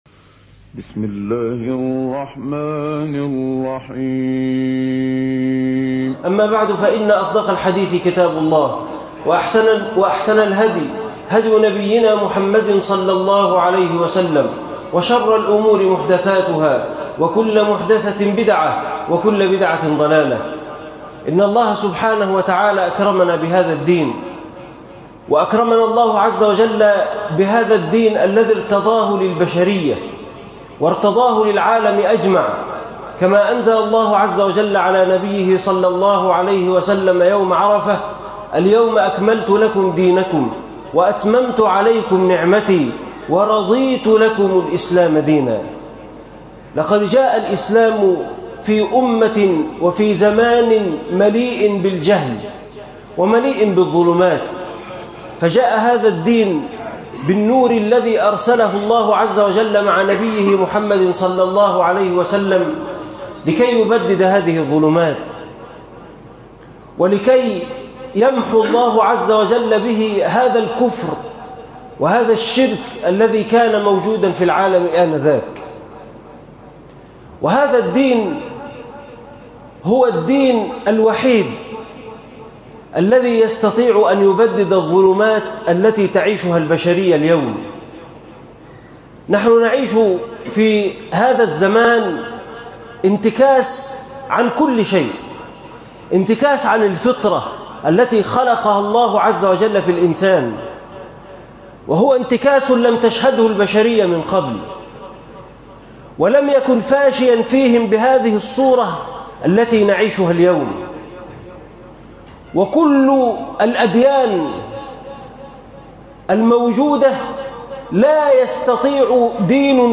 عنوان المادة إنه القرآن - سياجات العفة خطبة جمعة تاريخ التحميل الأحد 8 اكتوبر 2023 مـ حجم المادة 14.60 ميجا بايت عدد الزيارات 216 زيارة عدد مرات الحفظ 55 مرة إستماع المادة حفظ المادة اضف تعليقك أرسل لصديق